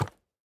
Minecraft Version Minecraft Version snapshot Latest Release | Latest Snapshot snapshot / assets / minecraft / sounds / mob / goat / step1.ogg Compare With Compare With Latest Release | Latest Snapshot
step1.ogg